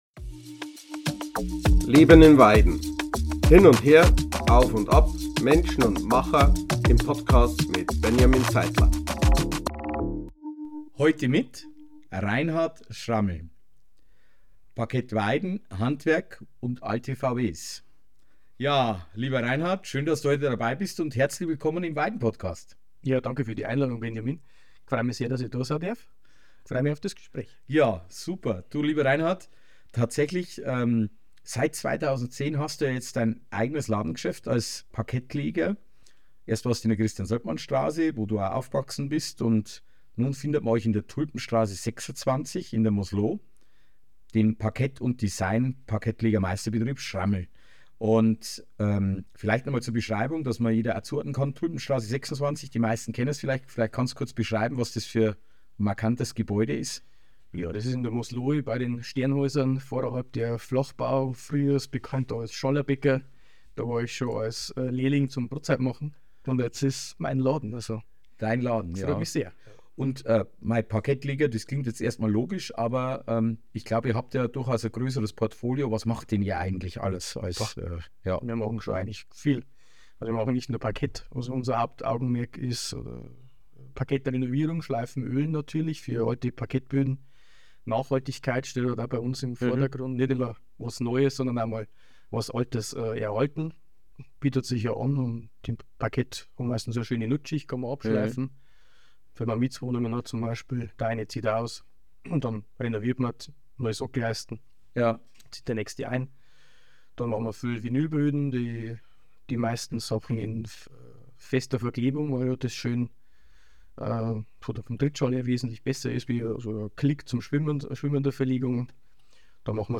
Ein Gespräch mit einem, der zupackt, Verantwortung übernimmt und dem das Handwerk am Herzen liegt – beruflich wie privat.